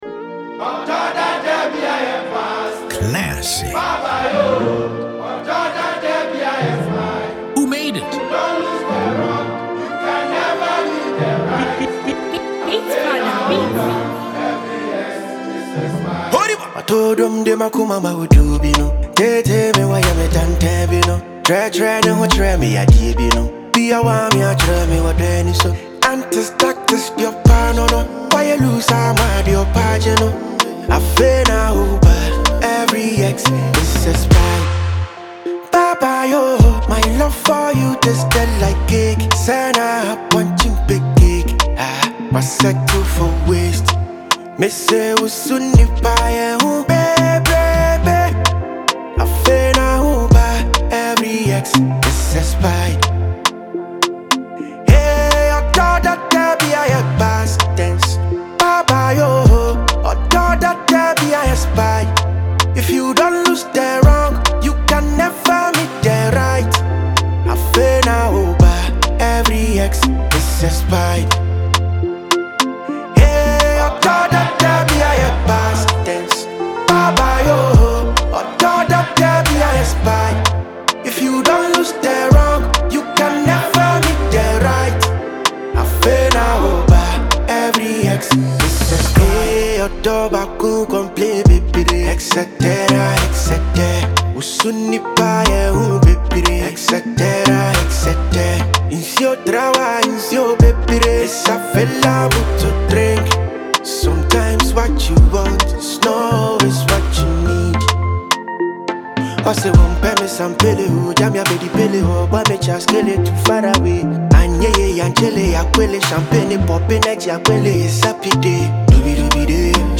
energetic Afrobeat/Afropop track
• Genre: Afrobeat / Afropop